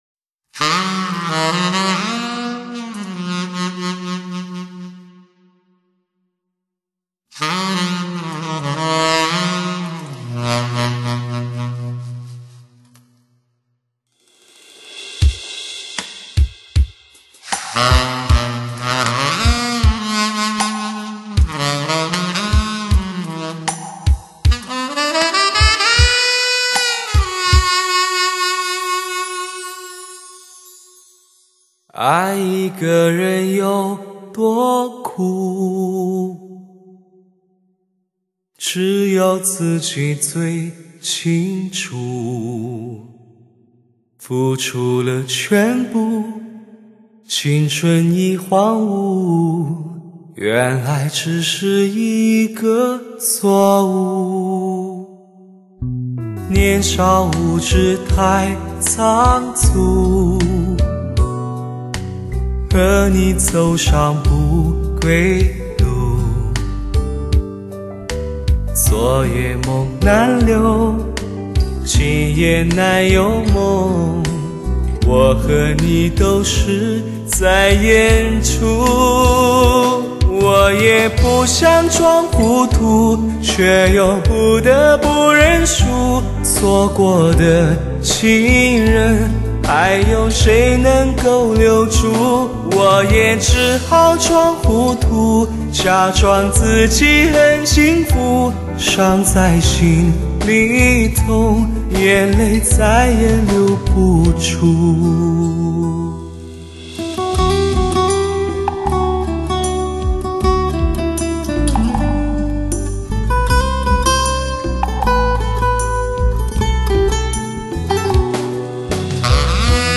360环绕3D音效 汽车音响测试天碟
聆听慰藉心灵的感怀男声